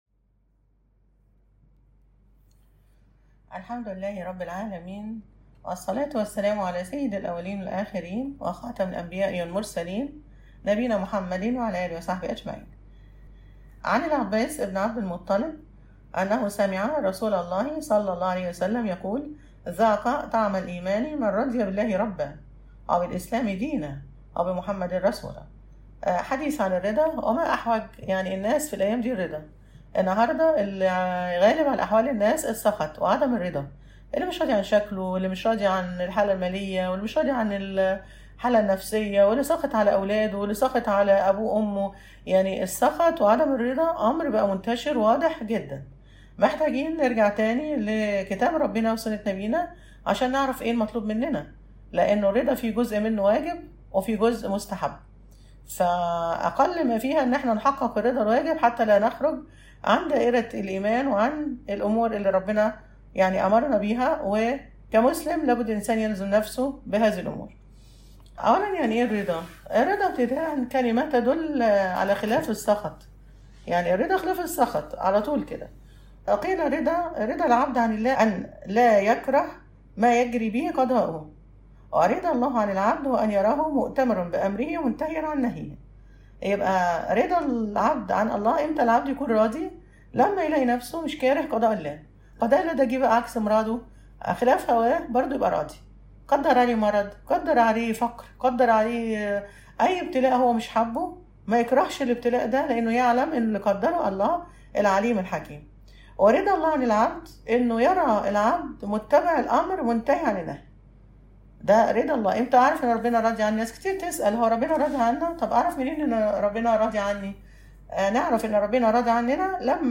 المحاضرة التاسعة_ “الرضا”